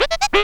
SAX SCRAT06L.wav